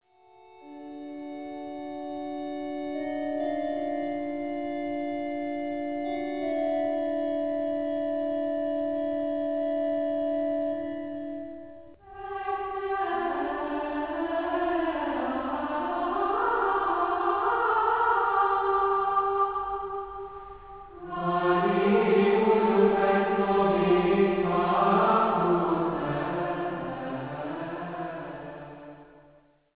orgue
gregorián reszponzóriumok a Genezis szövegére1."Ligatura"